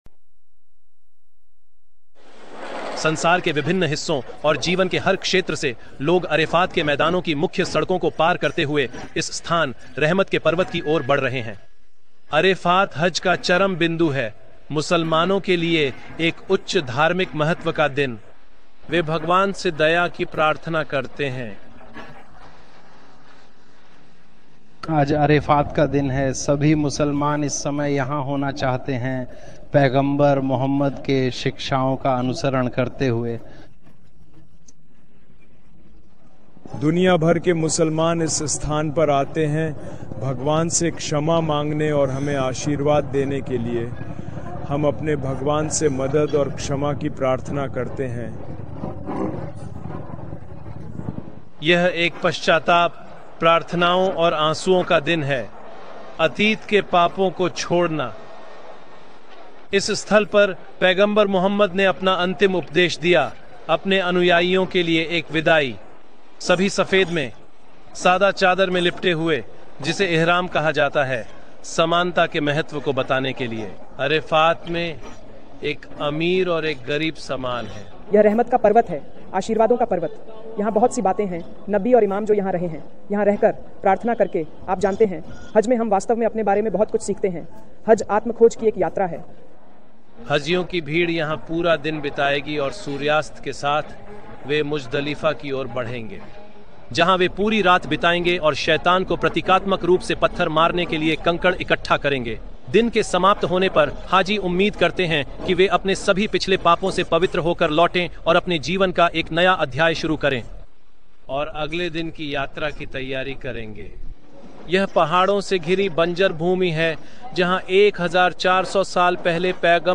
विवरण: यह वीडियो अल-जज़ीरा टीवी द्वारा हज की विशेषताओं पर आधारित समाचार रिपोर्ट है।